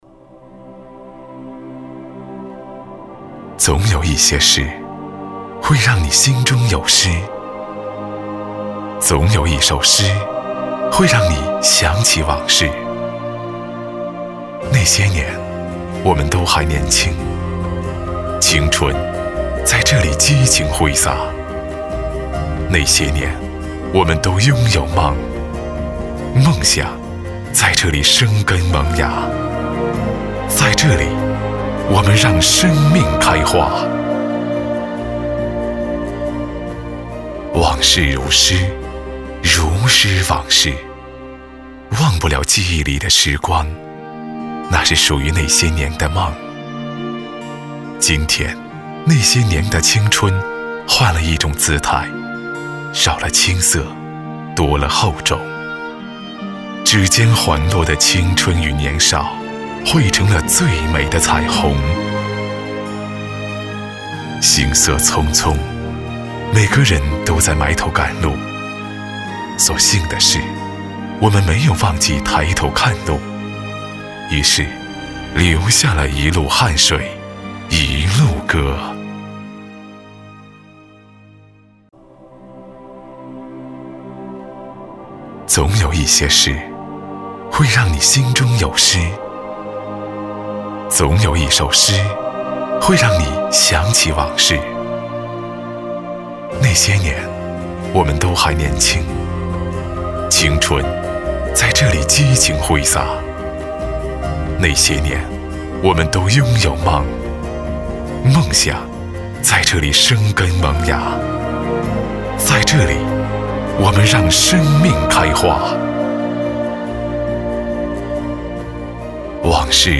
• 男S355 国语 男声 专题片-那些年我们一同走过-宣传专题-深沉 欢快 大气浑厚磁性|沉稳|科技感